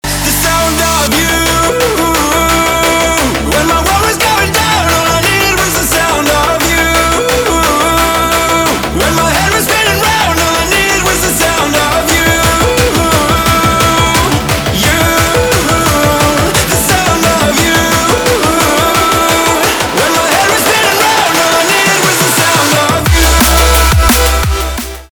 драм энд бейс
крутые , битовые , басы , качающие , громкие
дабстеп